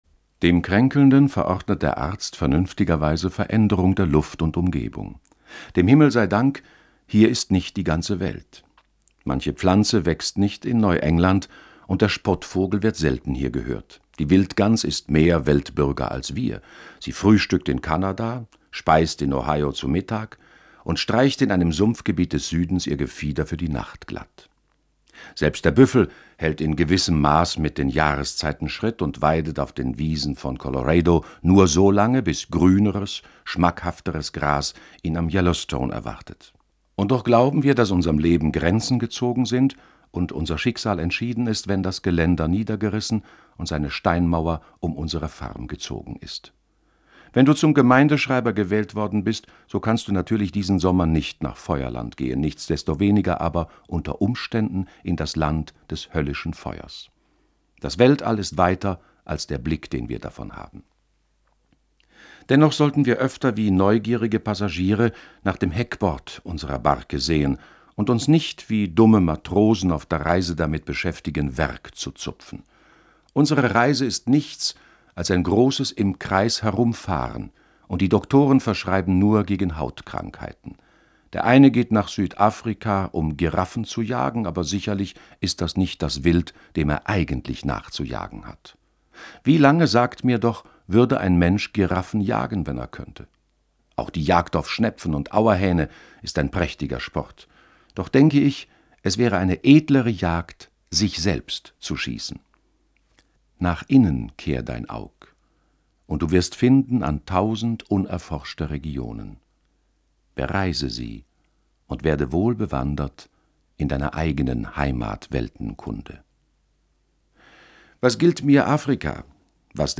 Im Lauf unserer Lesung haben wir ihn bei seinen Beobachtungen und Reflektionen einmal durch alle Jahreszeiten begleitet. Im letzten Kapitel seines Buches fasst Thoreau seine Überlegungen noch einmal in einer Art Glaubens-, oder besser: Lebensbekenntnis zusammen.